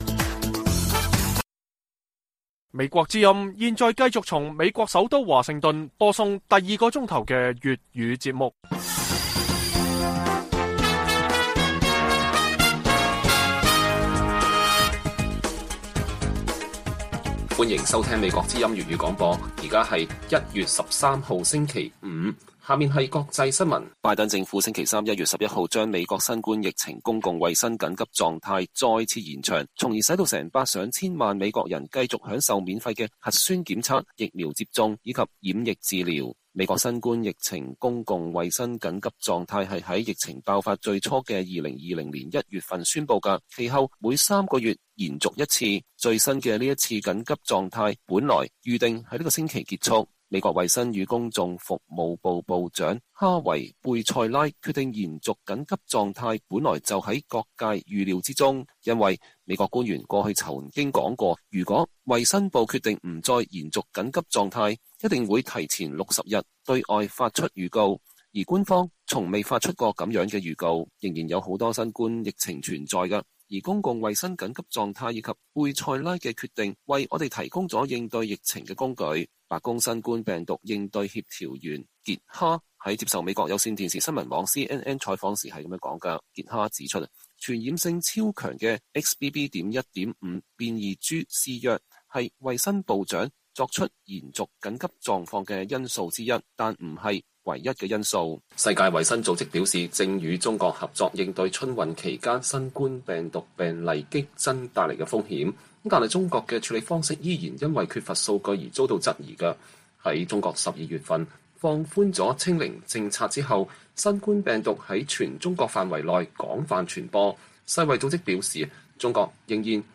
粵語新聞 晚上10-11點: 美中防疫政策不同調一鬆一緊，拜登延長公共衛生緊急狀態